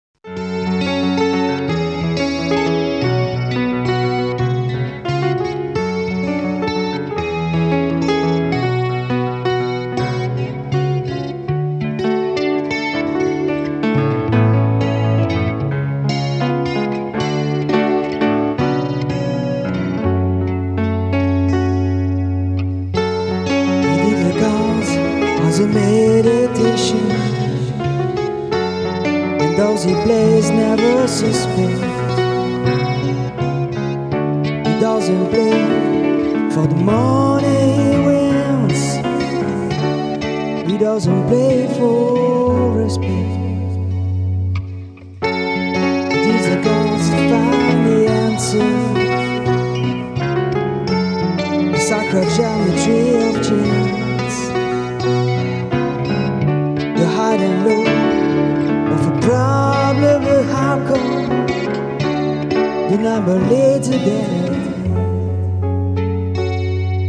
Le 05 et 06/08/2006 au studio "Mafaldine", Lamalou, France
Guitare
Claviers, Chant, Choeurs